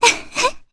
Cassandra-Vox-Laugh_jp.wav